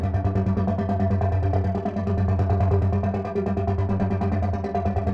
Index of /musicradar/dystopian-drone-samples/Tempo Loops/140bpm
DD_TempoDroneE_140-G.wav